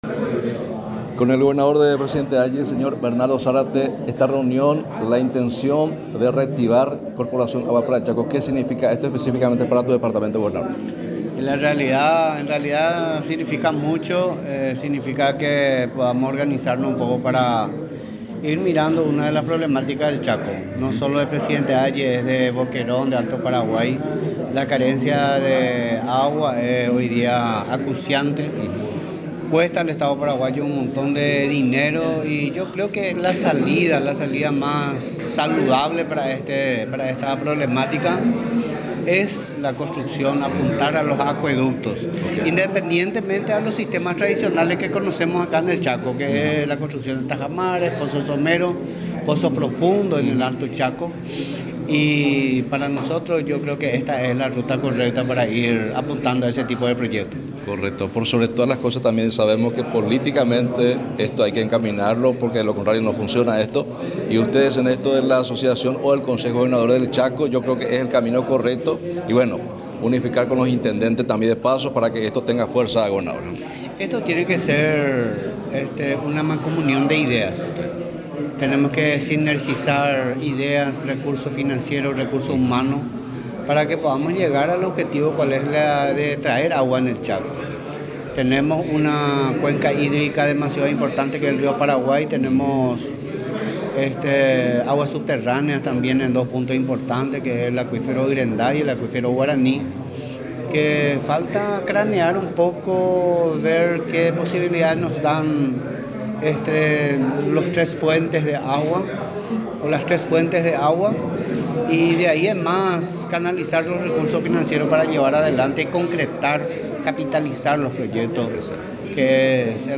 Entrevistas / Matinal 610
Entrevistado: Bernardo Zarate
Estudio Central, Filadelfia, Dep. Boquerón